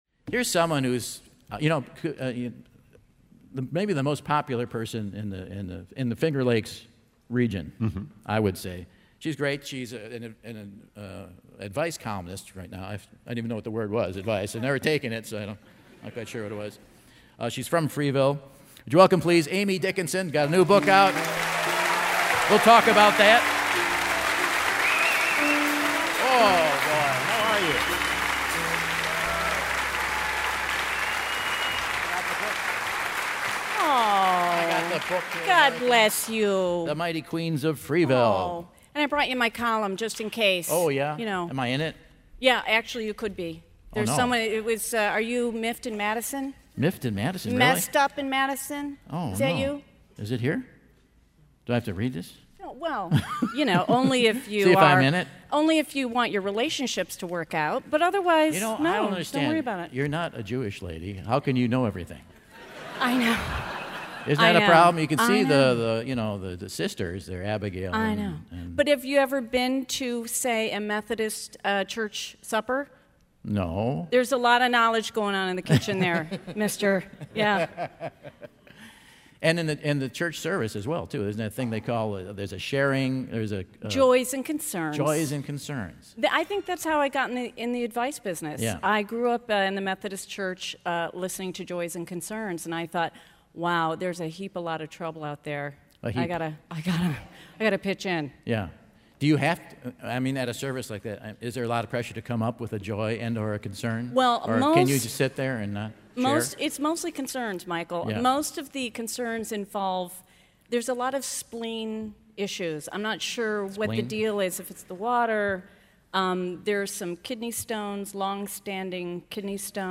Popular advice columnist, NPR contributer and Freeville, NY-born author Amy Dickinson joins Michael on stage!